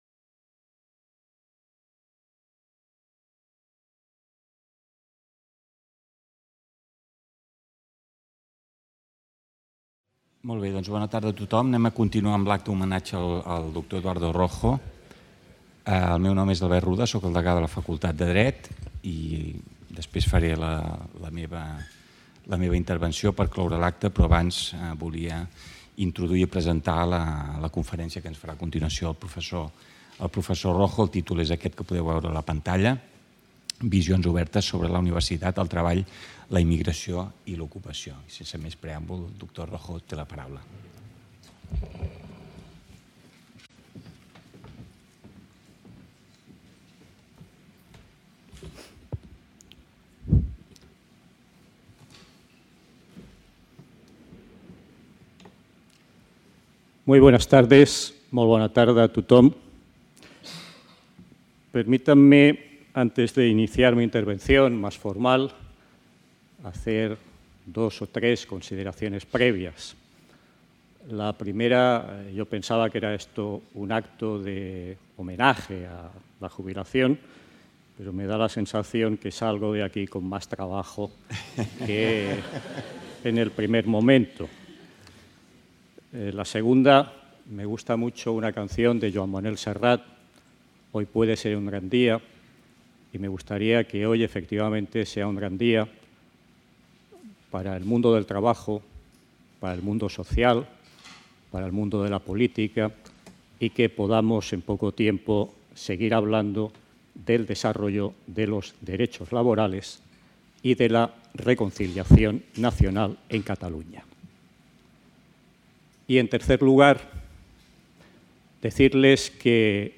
>  Jornada d’homenatge al Pr.